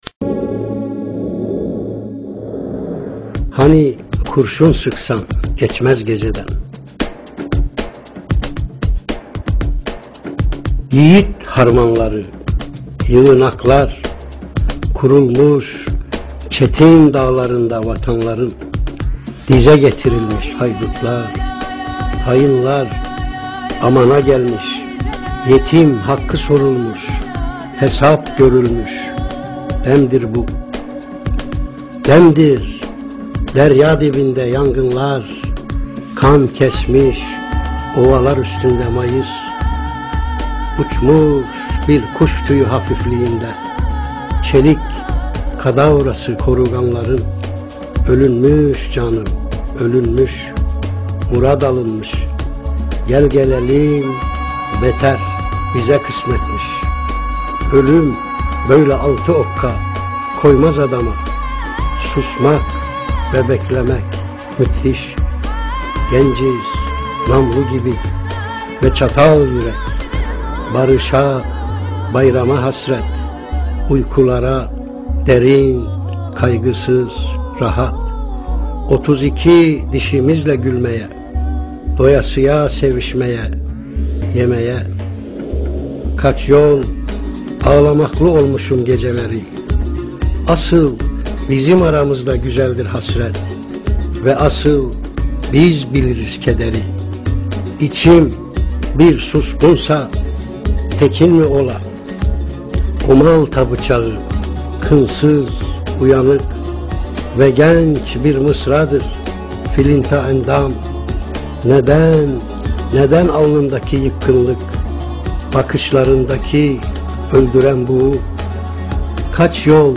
Ahmed Arif Kendi Sesi'nden Hani Kur�un S�ksan Ge�mez Geceden �iiri